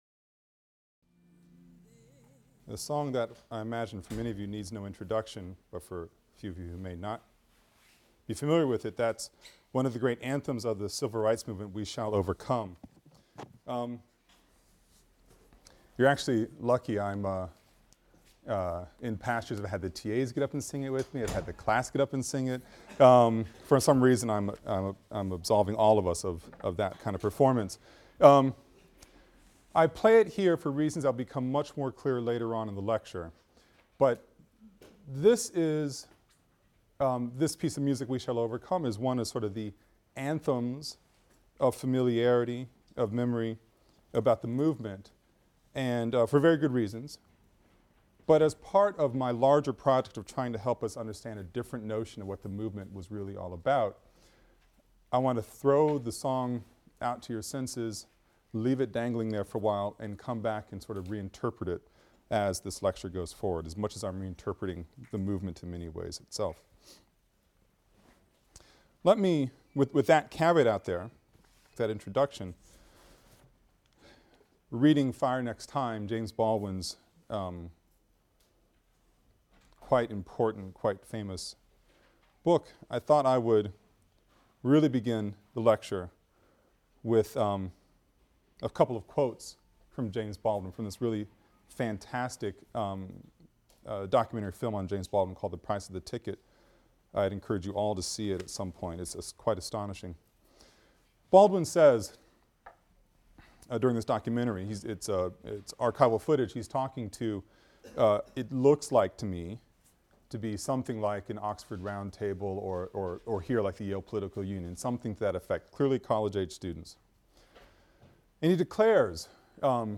AFAM 162 - Lecture 15 - From Sit-Ins to Civil Rights (continued) | Open Yale Courses